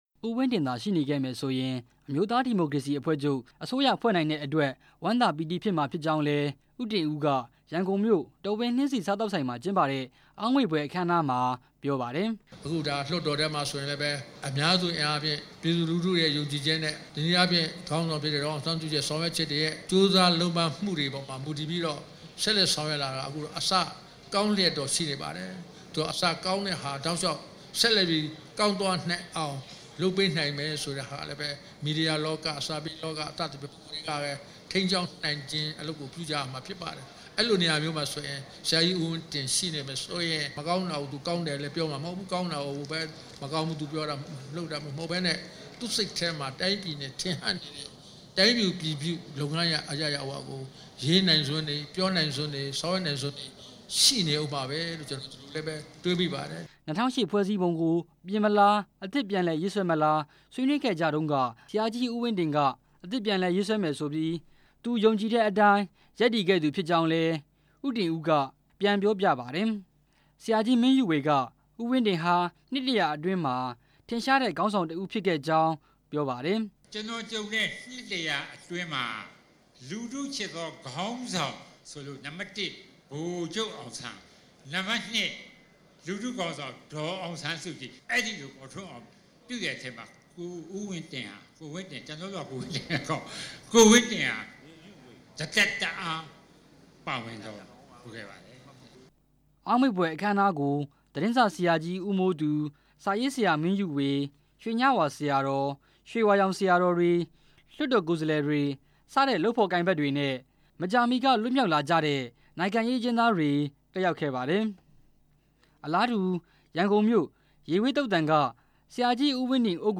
အမျိုးသားဒီမိုကရေစီ အဖွဲ့ချုပ် သဘာပတိအဖွဲ့ဝင် သတင်းစာဆရာကြီး ဟံသာဝတီ ဦးဝင်းတင် ကွယ်လွန်တဲ့ ၂ နှစ်ပြည့် အောက်မေ့ဖွယ် ဂုဏ်ပြုအခမ်းအနားကို ဒီနေ့ ရန်ကုန်မြို့ တော်ဝင်နှင်းဆီ စားသောက်ဆိုင်မှာ ကျင်းပခဲ့ပါတယ်။